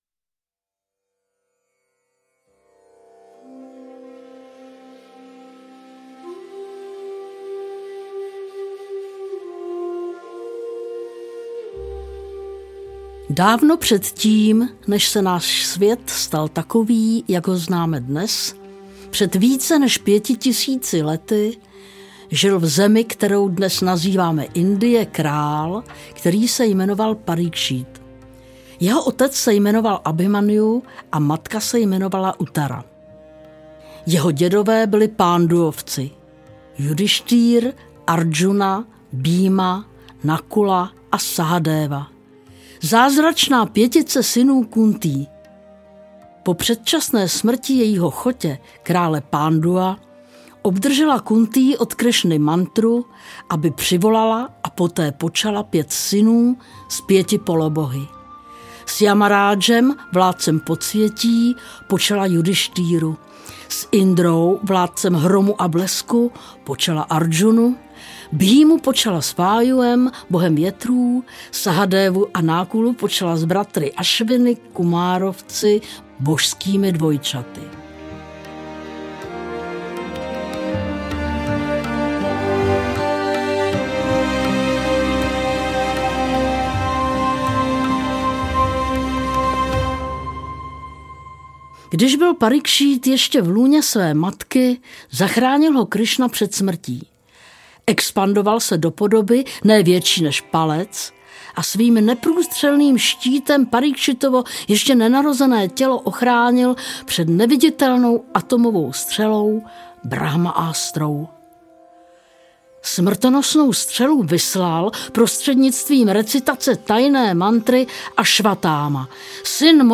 Krásný Bhágavatam díl 1. audiokniha
Ukázka z knihy